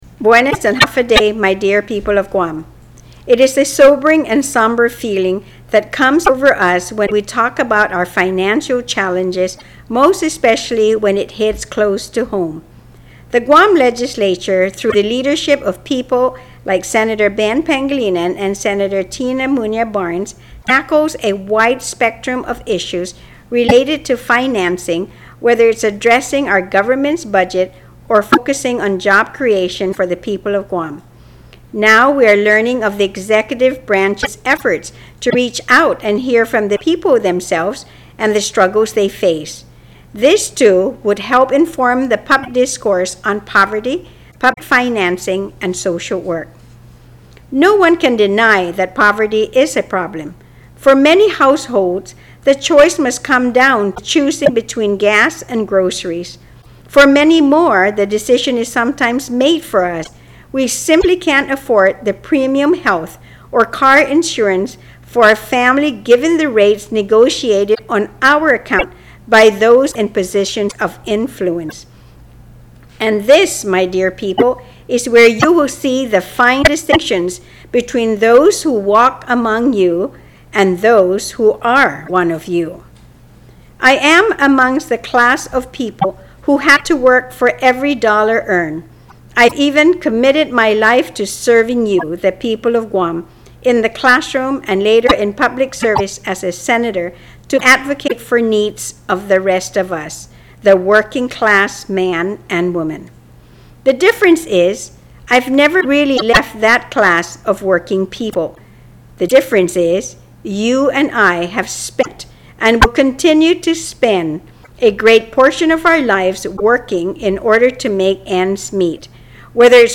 Speaker's Weekly Address - April 6